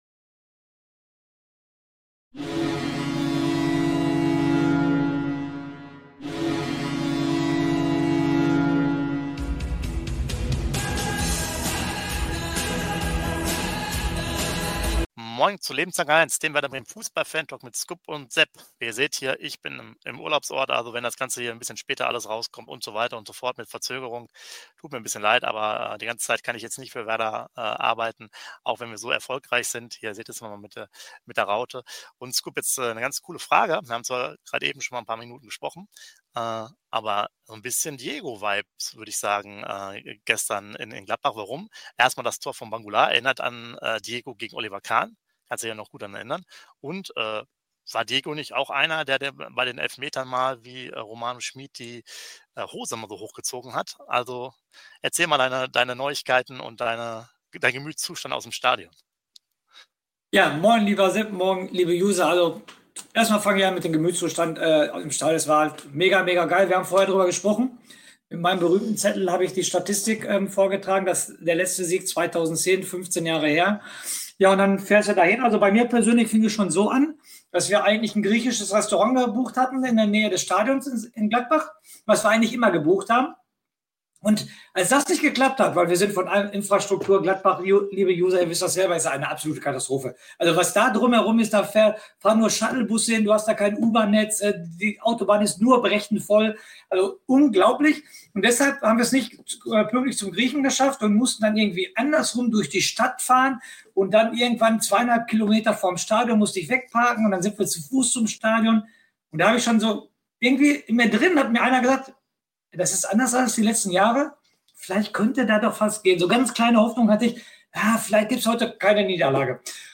Werder Bremen - News & Aktuelles vom 15.09.2025 - Bor. Mönchengladbach - Werder Bremen 0:4 ~ Werder Bremen - Fußball Fantalk Lebenslang-A1 Podcast